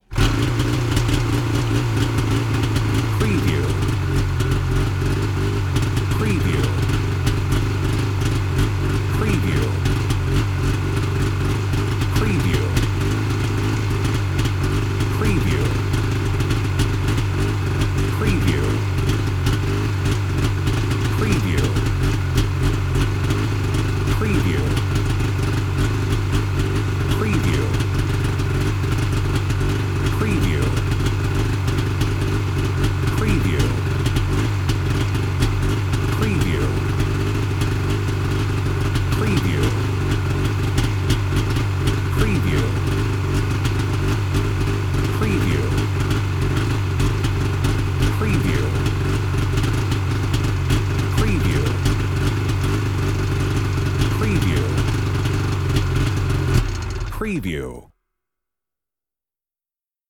Snowmobile: Start, Idle & Turn Off Wav Sound Effect #2
Description: Snowmobile starts, idles and turns off
Properties: 48.000 kHz 24-bit Stereo
Keywords: snowmobile, snow mobile, skidoo, ski-doo, ski doo, winter, engine, start, idle, idling, turn, off
snowmobile-start-idle-and-turn-off-preview-02.mp3